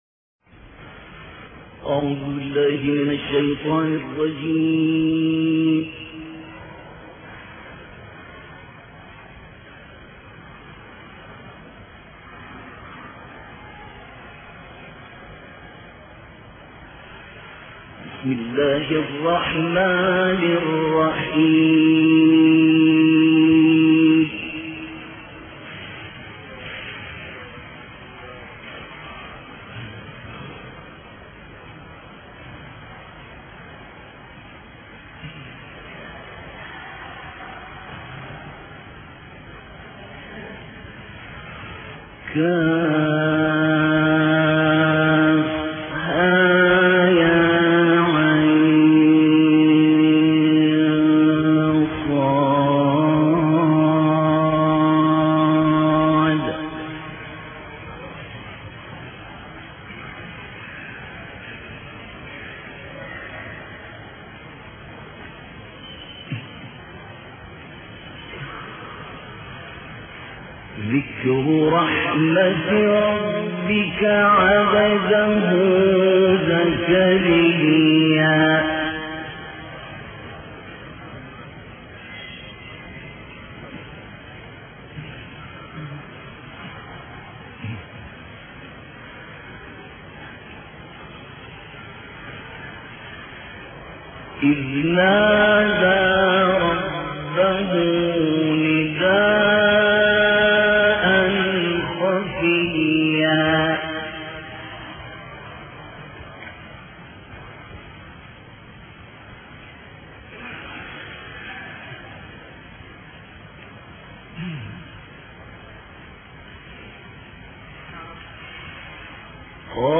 تلاوت آیاتی از سوره مریم توسط استاد عبدالمنعم طوخی
تلاوت قرآن کريم